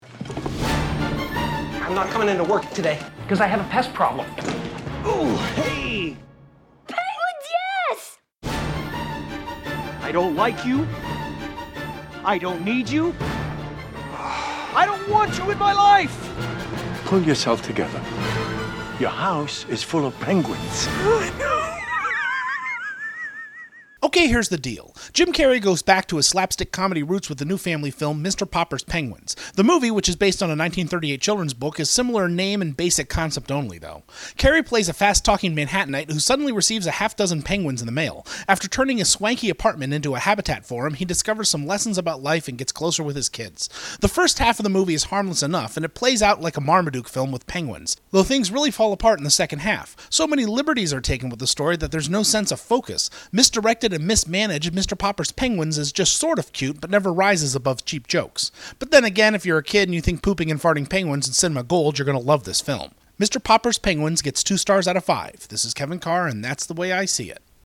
Movie Review: ‘Mr. Popper’s Penguins’